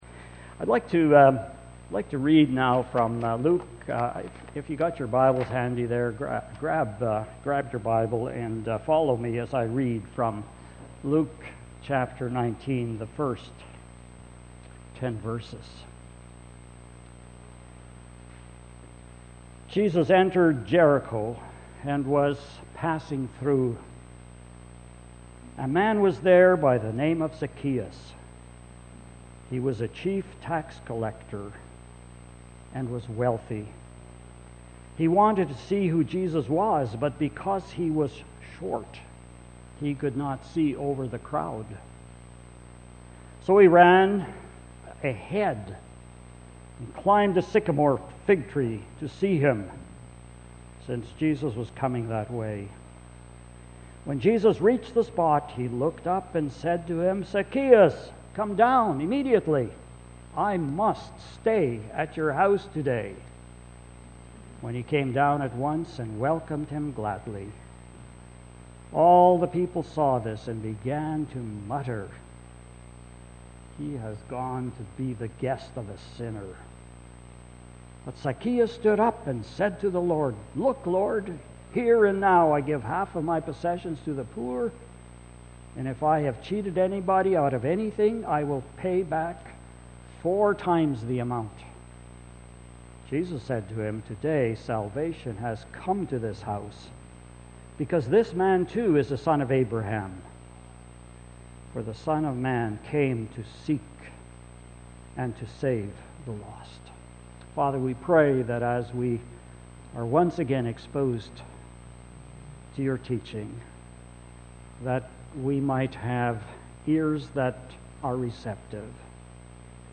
Sermons - Ambleside Baptist Church